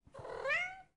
ccc_meow-319be140.mp3